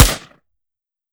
fps_project_1/5.56 M4 Rifle - Gunshot B 002.wav at f021e3cea41e2cc72e05971f61e470a9e8391ea6